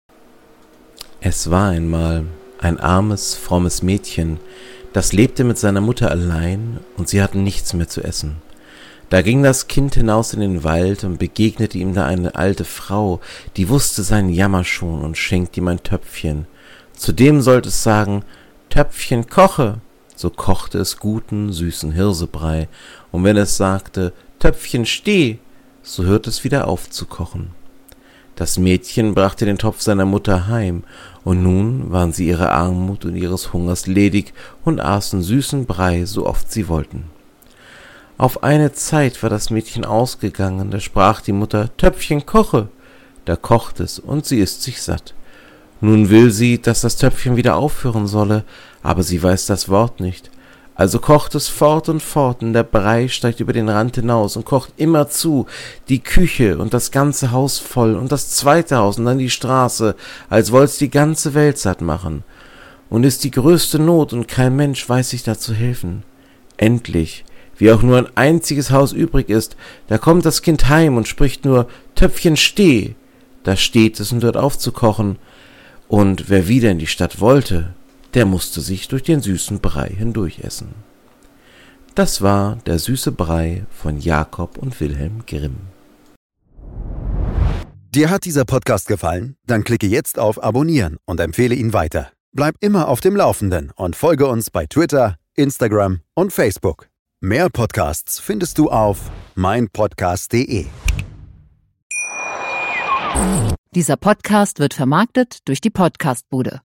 In diesem kleinen Podcast Projekt lese ich Märchen vor.